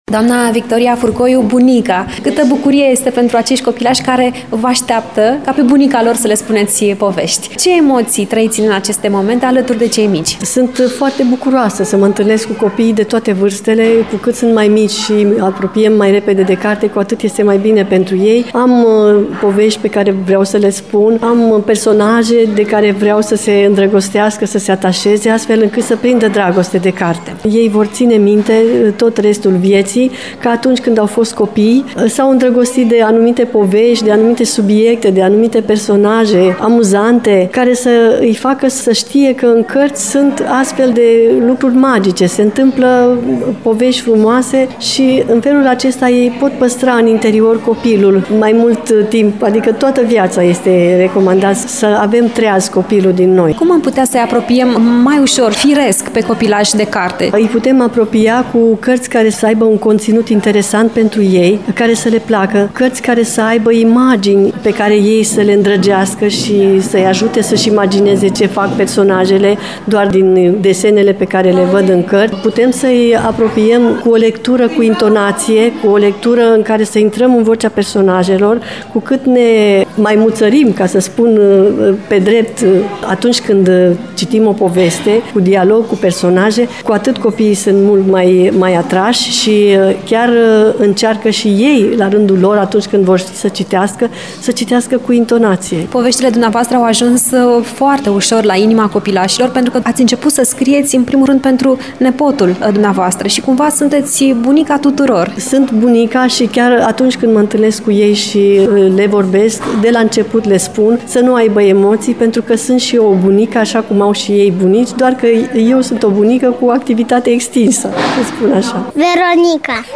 Urmează un interviu